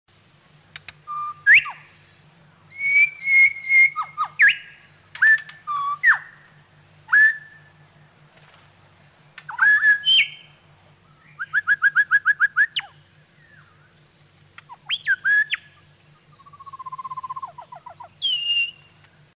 Nogle af dem er formidable sangere, som for eksempel den Grå Tornskadedrossel
(08), der har en sang, der kan minde om Nattergalens.